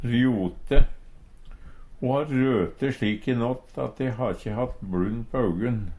rjote - Numedalsmål (en-US)